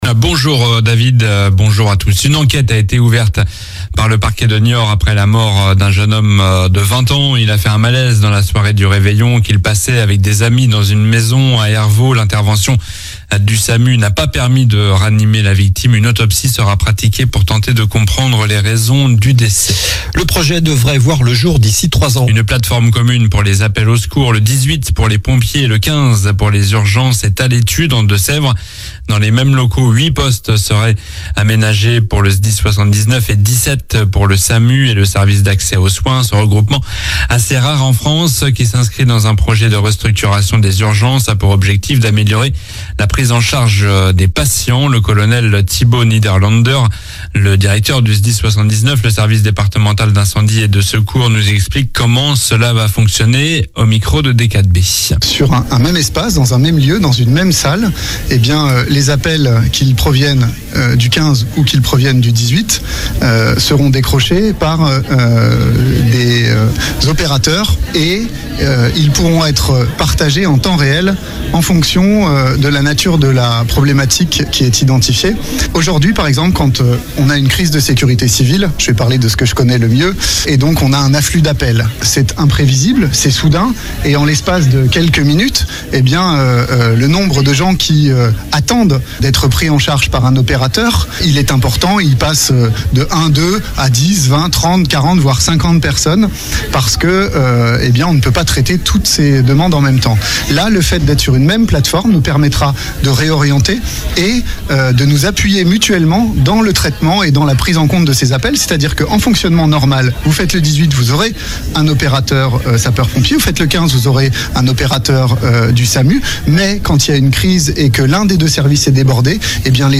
L'info près de chez vous